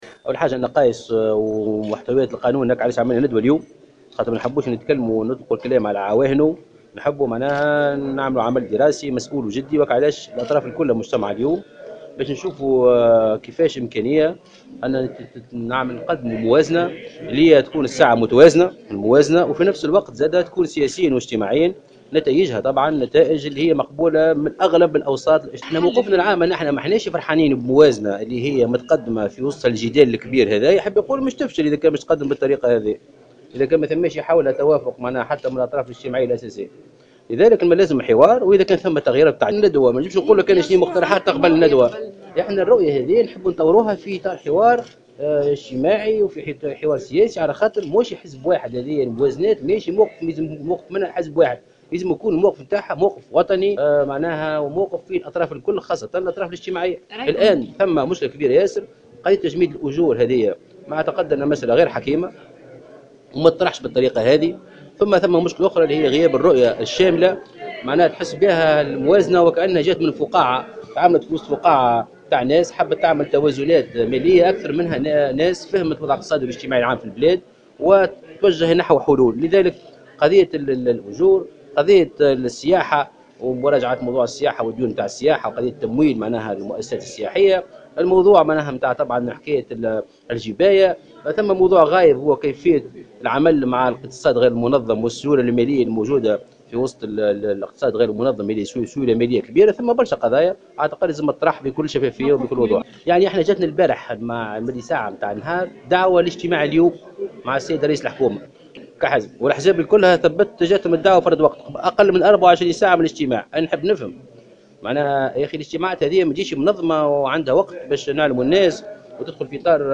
Le secrétaire général du mouvement "projet de la Tunisie", Mohsen Marzouk, est intervenu ce jeudi 3 novembre 2016 sur les ondes de Jawhara FM en marge d'une conférence de presse sur la loi de finances.